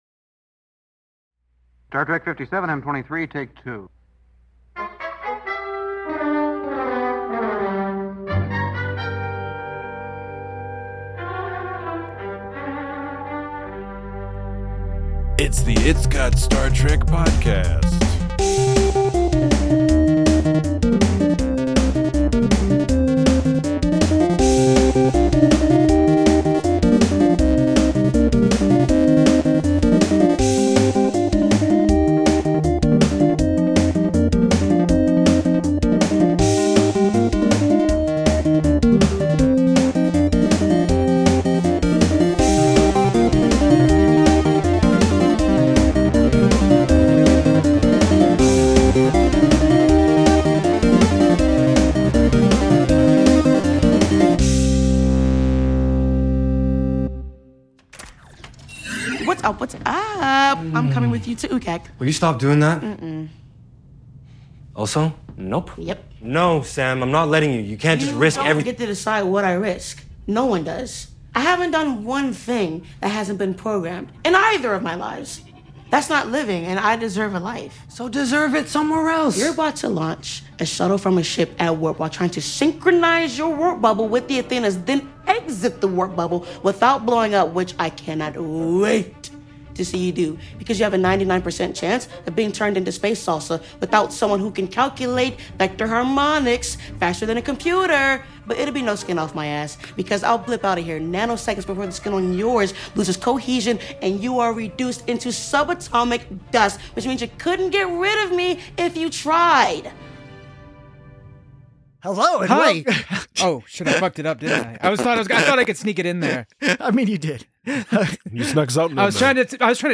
Join your celebratory hosts as they discuss gritty alien trading posts, penultimatums, and Harry and the Hendersons.